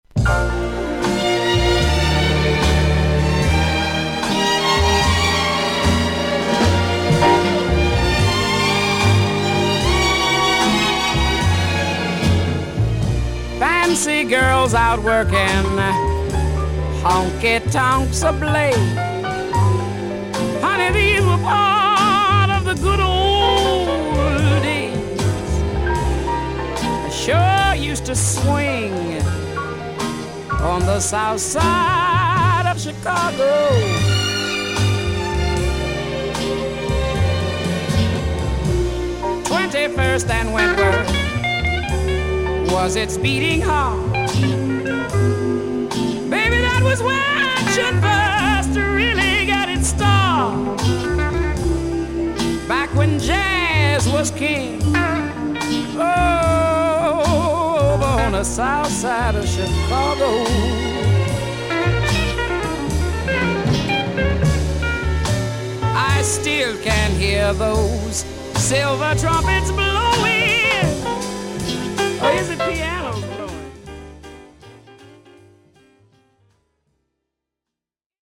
女性シンガー。
VG++〜VG+ 少々軽いパチノイズの箇所あり。クリアな音です。